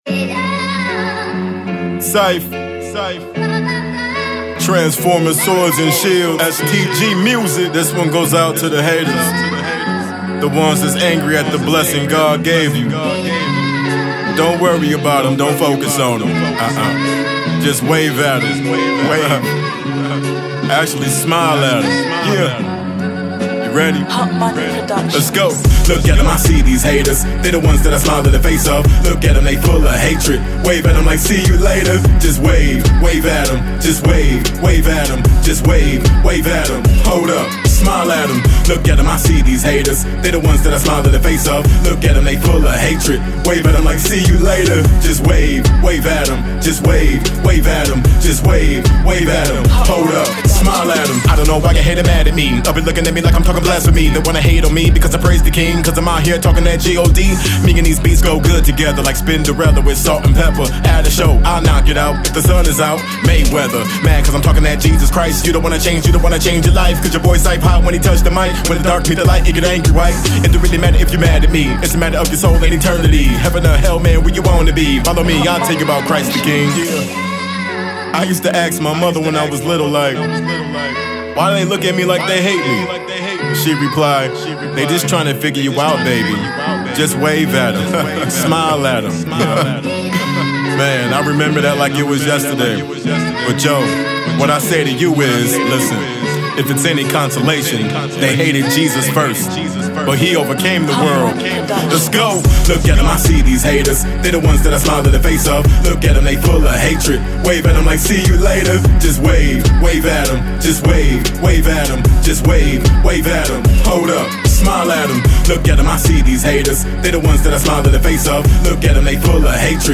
Christian/Gospel